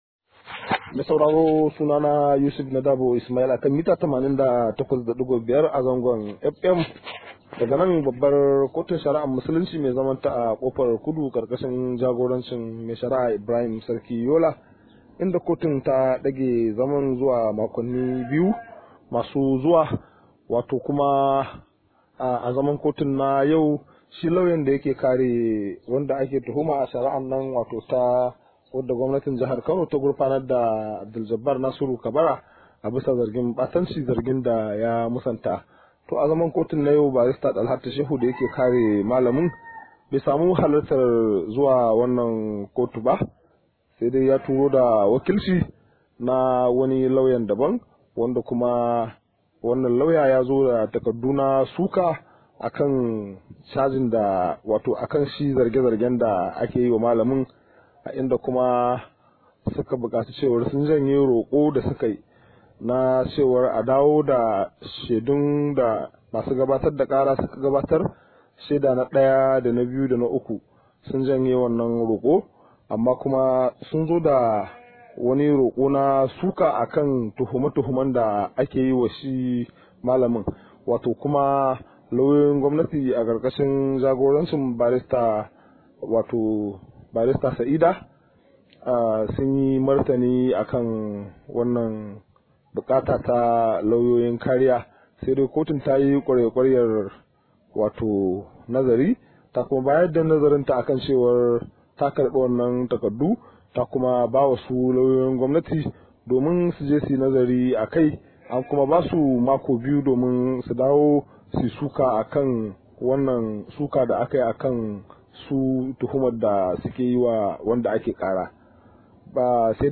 Rahoto: Lauyan Abduljabbar bai halarci zaman kotu ba